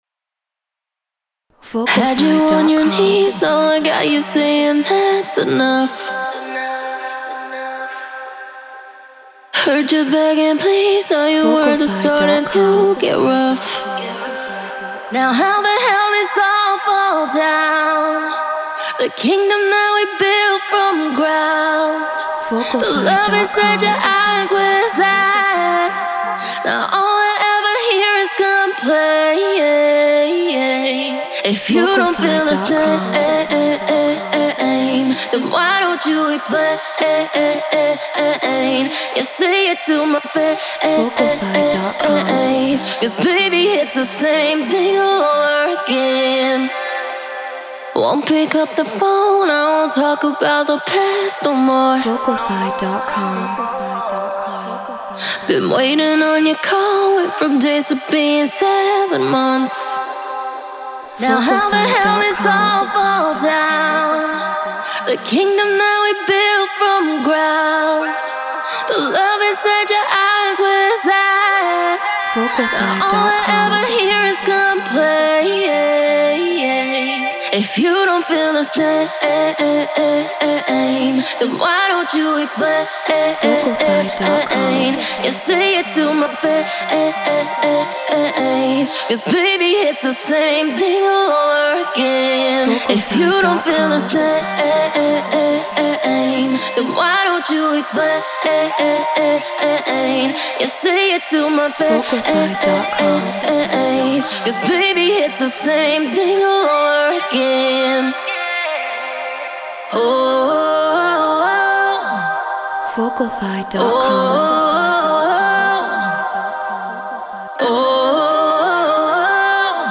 House 125 BPM D#maj
Human-Made
Treated Room